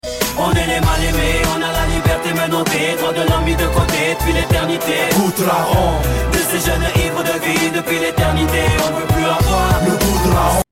• Качество: 128, Stereo
Хип-хоп
Rap
речитатив
Французский хип-хоп